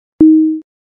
Звуки восклицаний
На этой странице собраны разнообразные звуки восклицаний: удивление, радость, испуг и другие эмоции.